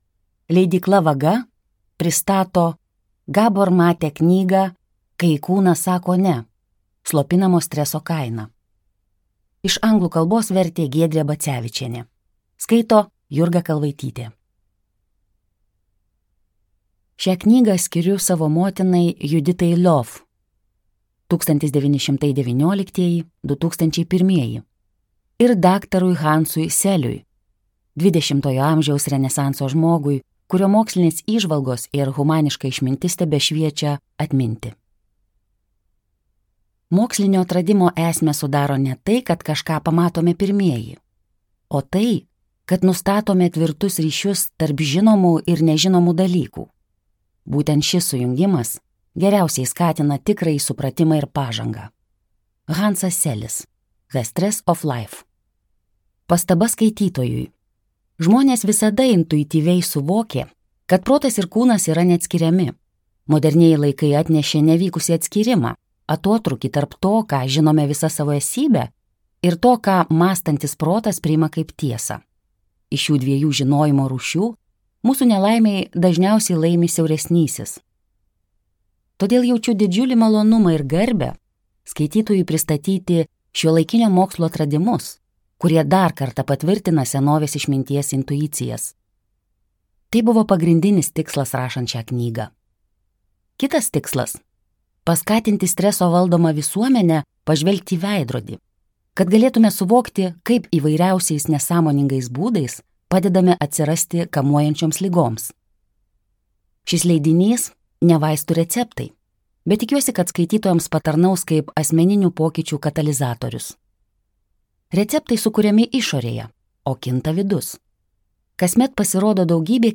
Skaityti ištrauką play 00:00 Share on Facebook Share on Twitter Share on Pinterest Audio Kai kūnas sako ne.